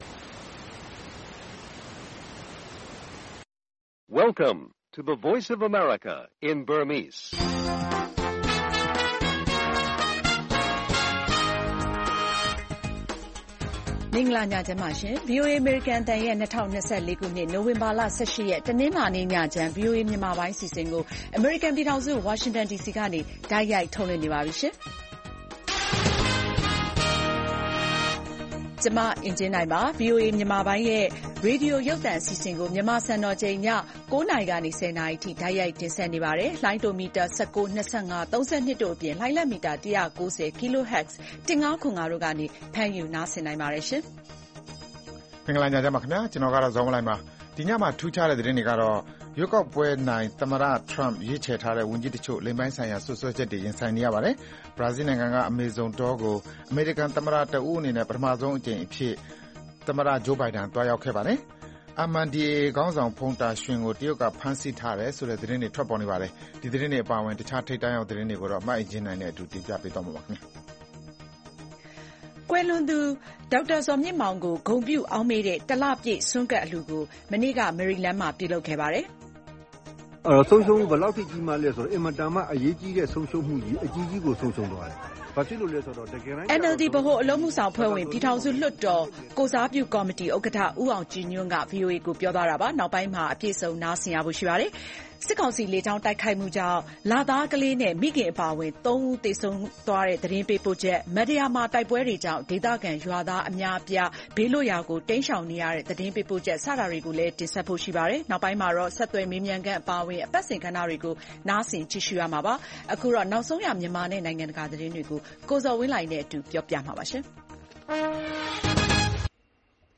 ရွေးကောက်ခံသမ္မတထရမ့် အစိုးရသစ်အဖွဲ့ဝင် အဆိုပြုချက် အငြင်းပွားစရာတွေနဲ့ကြုံ၊ စစ်ကောင်စီ လေကြောင်းတိုက်ခိုက်မှုကြောင့် ကွတ်ခိုင်မှာ လသားကလေးနဲ့ မိခင်အပါအဝင် ၃ ဦးသေဆုံး၊ NUG လူ့အခွင့်အရေးဝန်ကြီး ဦးအောင်မျိုးမင်းနဲ့ တွေ့ဆုံမေးမြန်းခန်၊ စီးပွားရေး၊ လူမှုရှုခင်း သီတင်းပတ်စဉ် အစီအစဉ်တွေကို တင်ဆက်ထားပါတယ်။